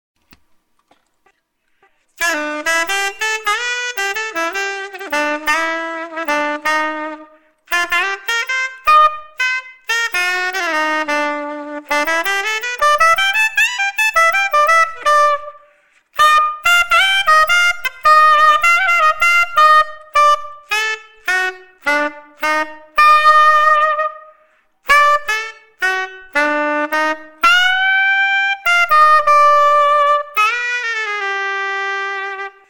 Doodle from the Conn, recorded whilst trying out a Sony ECM-909 mic direct into the PC.  The little bit of sound distortions seems to suggest I may just need a foam shield.
Used with a MojoBari  'tweaked" vintage Meyer Bb soprano ebonite mouthpiece, as you can see it's well onto the cork, and 2.5 Rico Plasticover reed gives a very strident sound.
roxio-csop-base-room20-pluseq-doodle1a.mp3